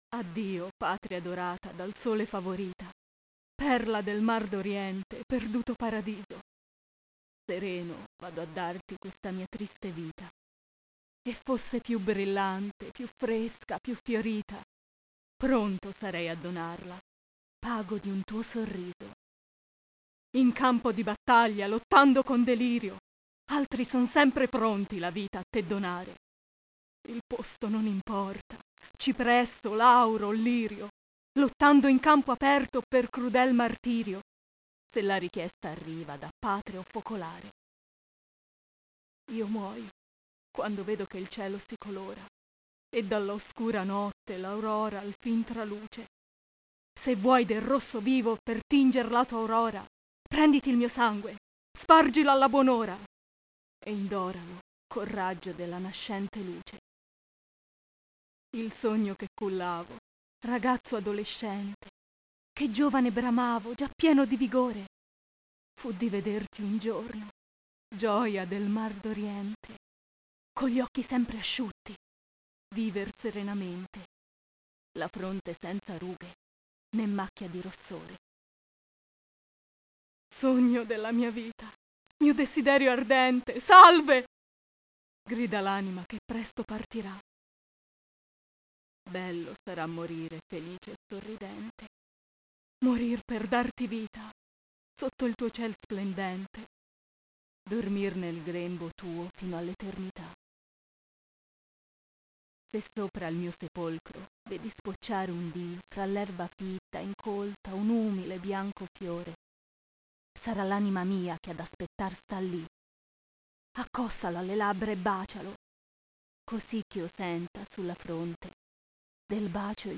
recitazione